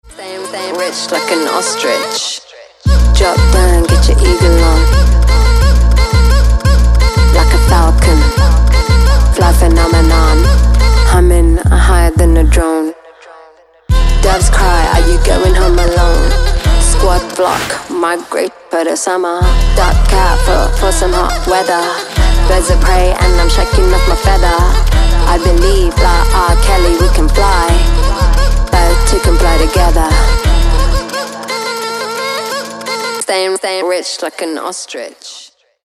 • Качество: 224, Stereo
женский вокал
Хип-хоп
Electronic
club
Bass
Alternative Hip-hop